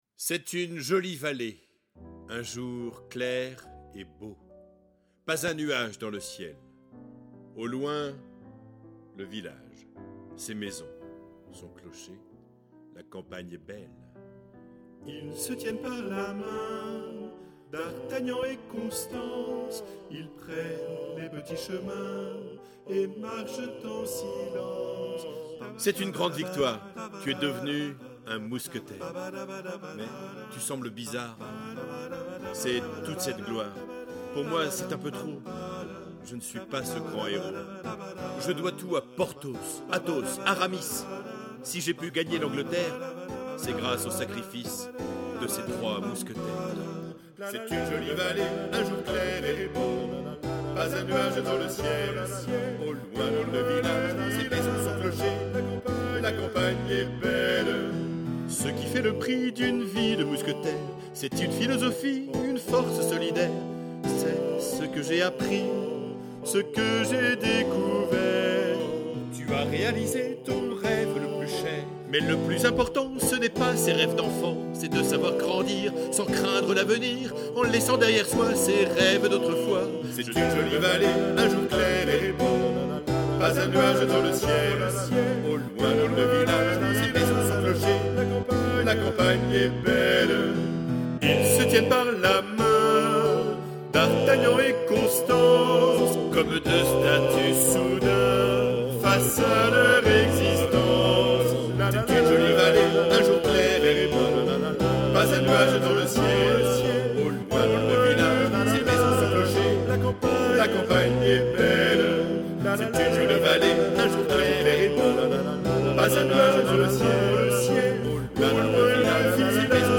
ATTENTION : ces fichiers audio comportent peu ou pas de nuances, il ne s'agit (normalement!) que des bonnes notes à la bonne place
avec la bonne durée le plus souvent chantées par des voix synthétiques plus ou moins agréables .